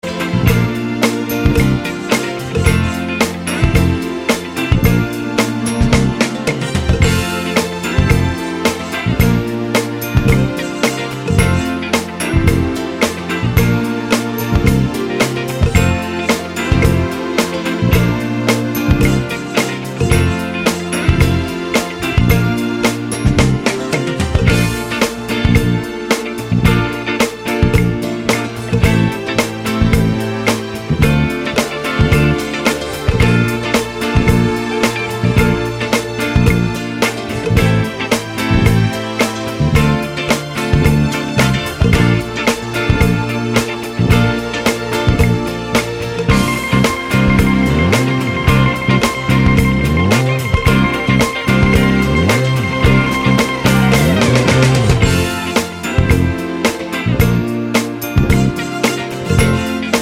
no Backing Vocals Disco 3:49 Buy £1.50